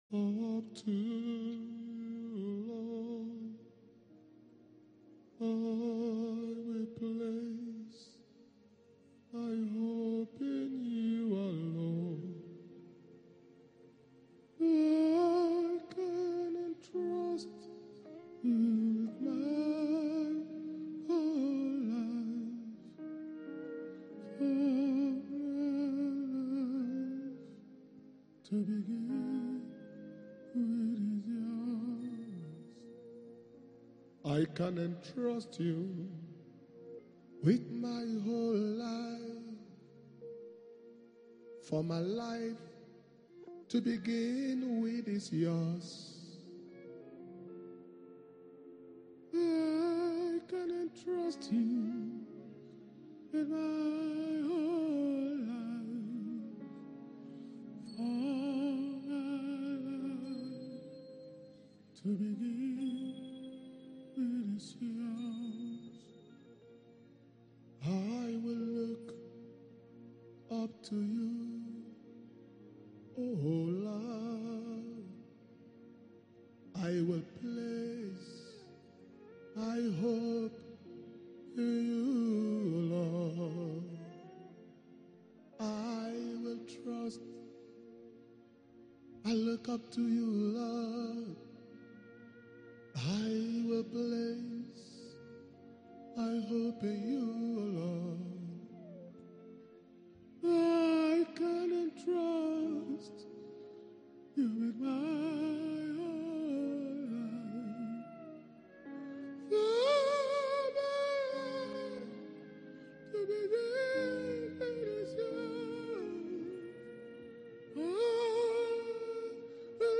Power Communion Service Message